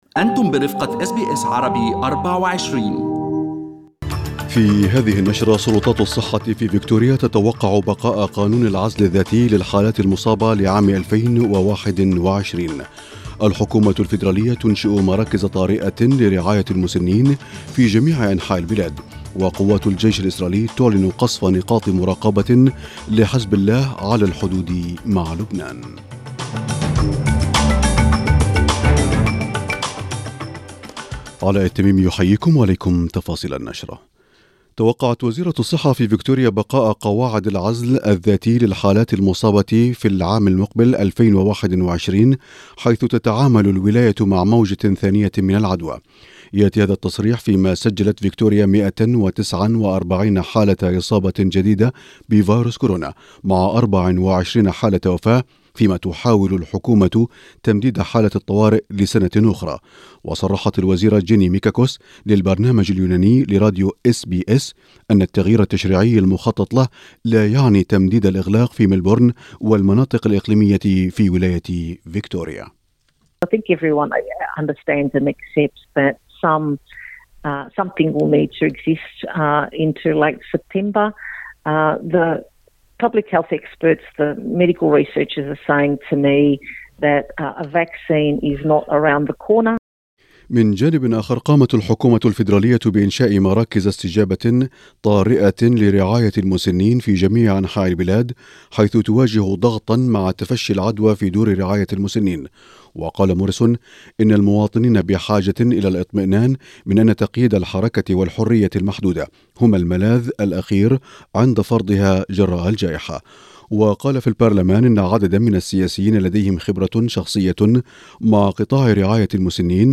نشرة أخبار المساء 26/8/2020